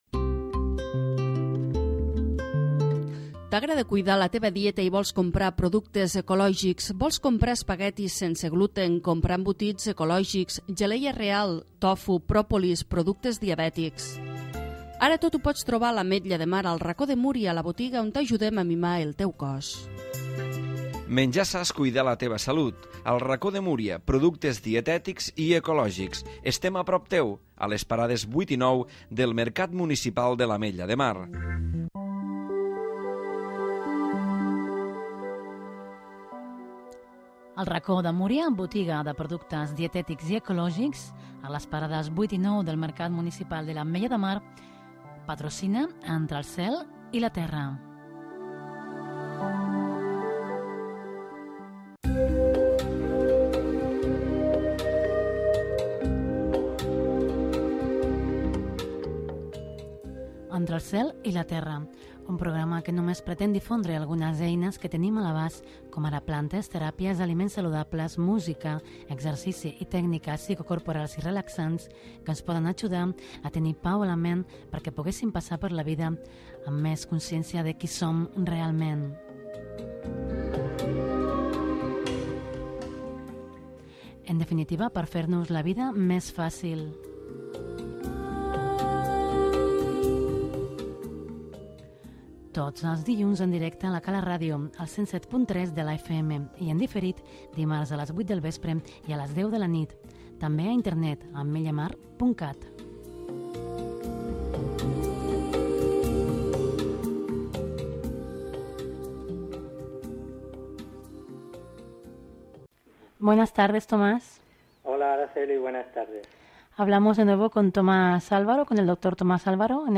El programa es completa amb música new age.